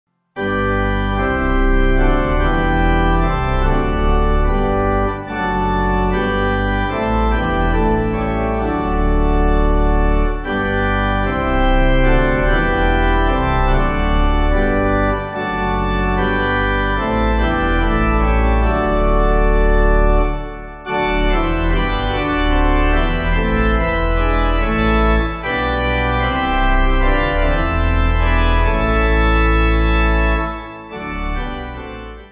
7.6.7.6.D
(CM)   4/G